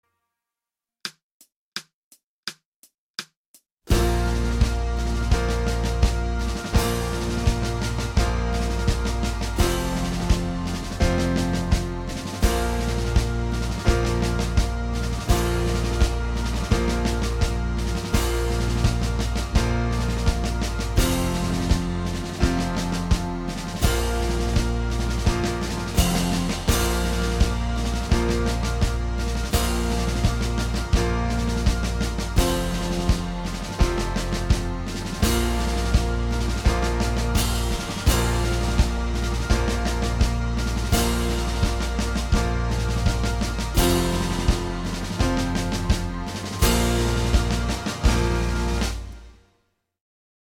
TROMBA SOLO • ACCOMPAGNAMENTO BASE MP3
Trombone